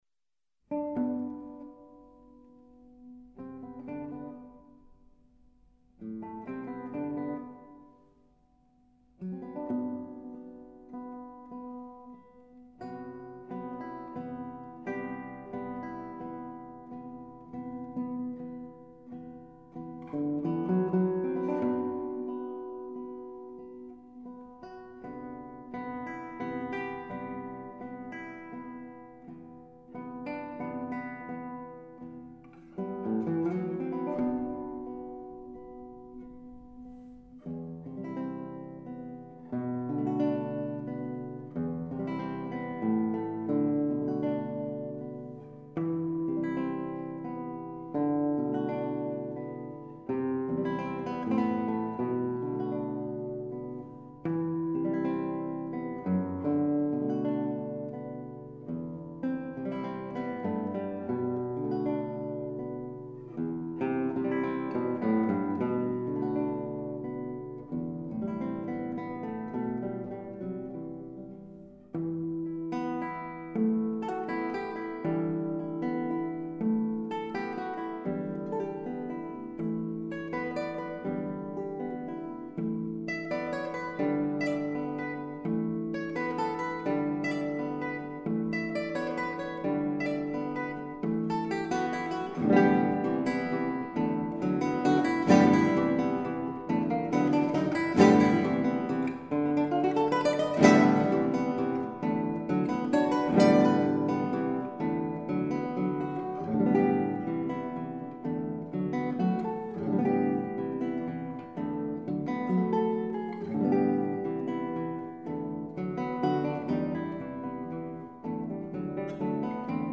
Genre: Classical. Guitar